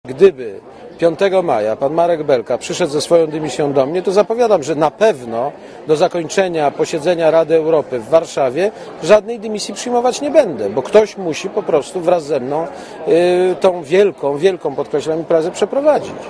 * Mówi Aleksander Kwaśniewski*